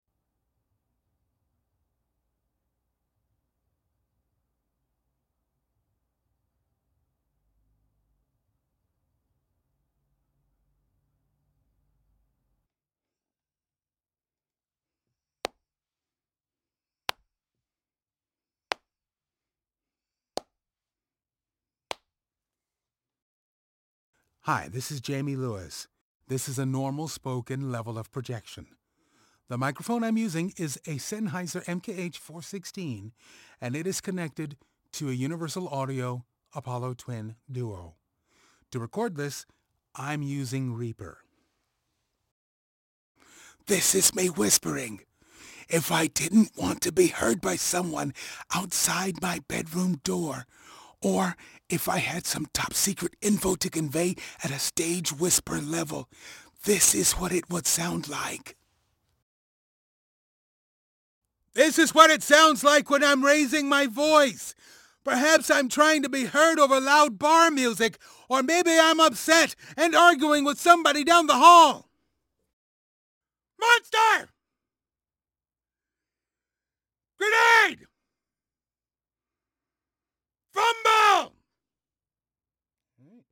Male
American English
Natural, Smooth, Warm, Assured, Authoritative, Bright, Confident, Cool, Corporate, Deep, Engaging, Friendly, Gravitas, Versatile
Microphone: Neumann TLM103, Sennheiser MKH416